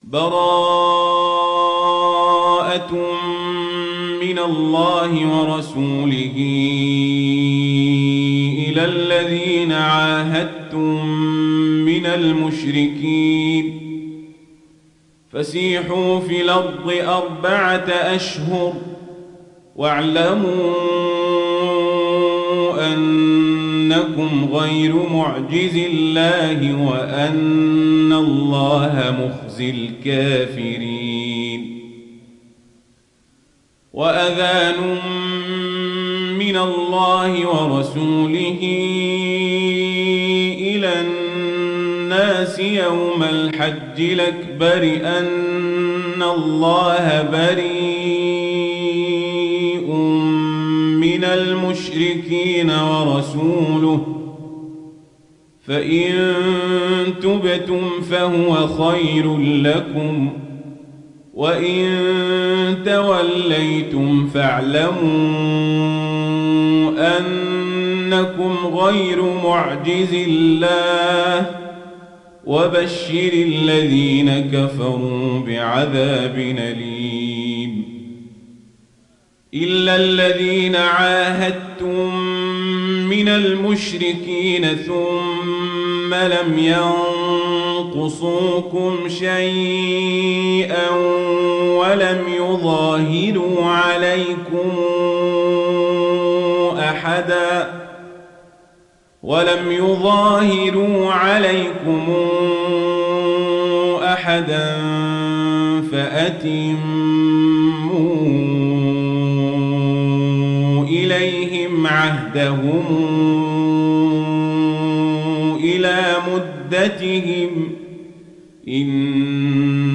تحميل سورة التوبة mp3 بصوت عمر القزابري برواية ورش عن نافع, تحميل استماع القرآن الكريم على الجوال mp3 كاملا بروابط مباشرة وسريعة